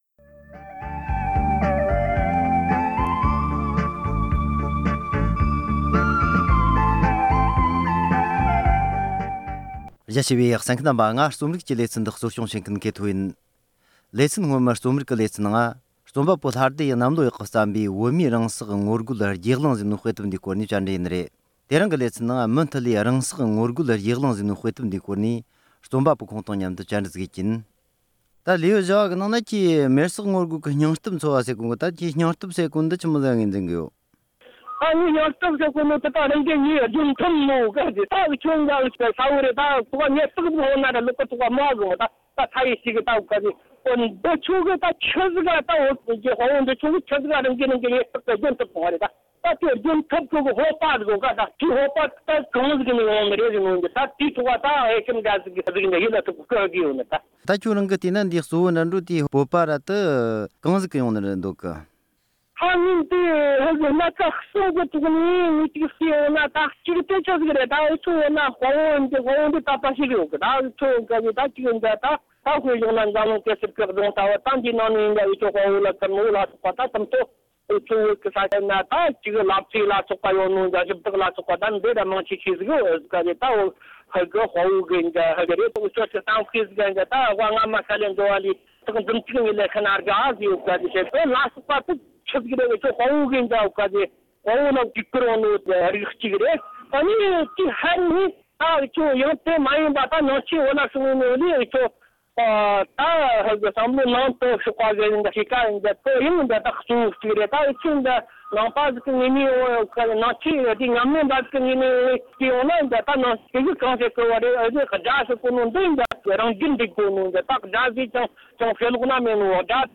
བཅར་འདྲི།